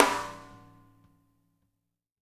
-SHUFF SN3-R.wav